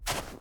tbd-station-14/Resources/Audio/Effects/Footsteps/snowstep4.ogg at 0bbe335a3aec216e55e901b9d043de8b0d0c4db1
snowstep4.ogg